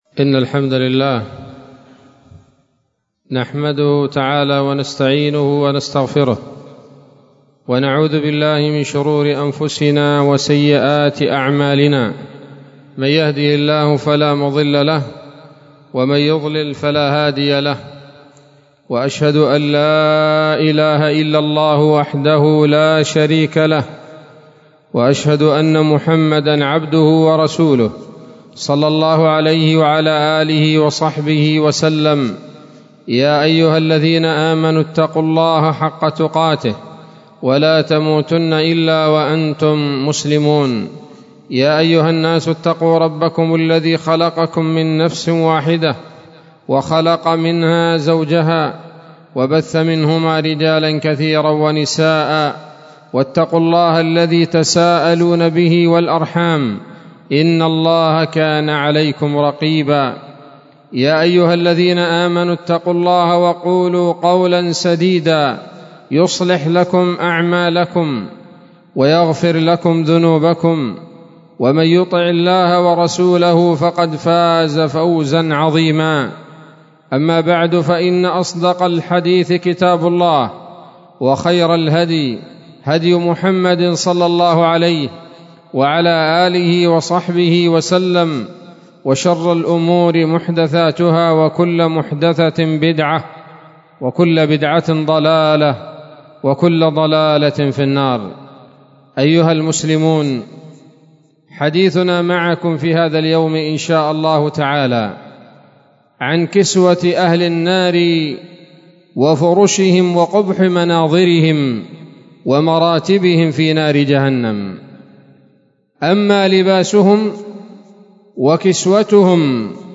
خطبة جمعة بعنوان: (( كسوة أهل النار وقبح مناظرهم وتفاوتهم في العذاب )) 13 صفر 1444 هـ، دار الحديث السلفية بصلاح الدين